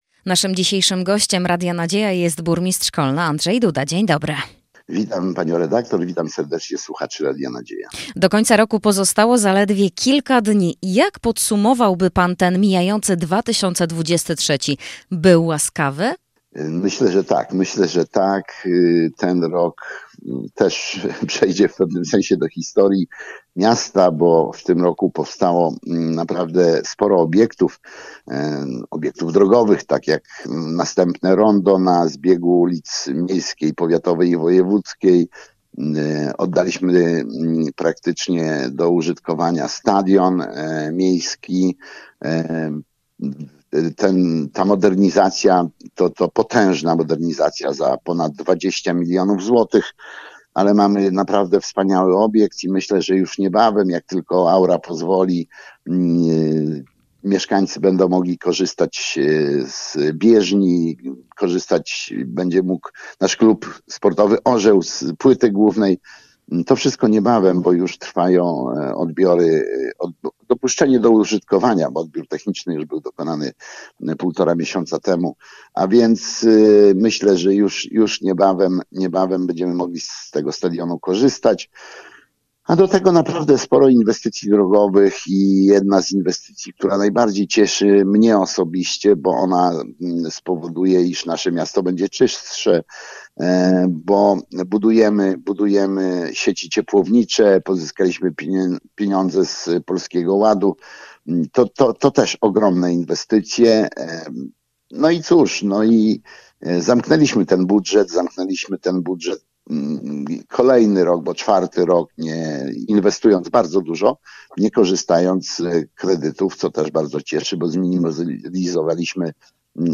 Gościem dnia Radia Nadzieja był Burmistrz Kolna, Andrzej Duda.